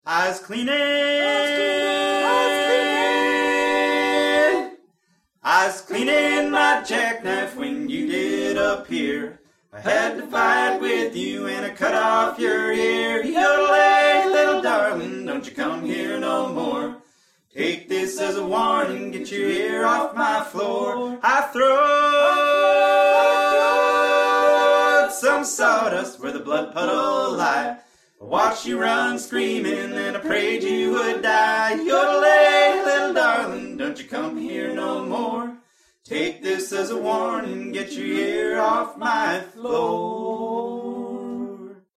Here’s a few more experiments with the Line6 TonePort UX2 hardware / GearBox software, this time at the Paint Branch Ramblers practice for November 20, 2008.
Normally we go right into “Down Yonder” after this, but I wanted to hear what just our vocal intro would sound like with an attempt to use Audacity’s noise removal feature to get rid of some of the hum from the fluorescent lights in my apartment.
Equalized using Decca FFRR 78 setting, which gives a little more of a trebly edge than the RCA Victor setting.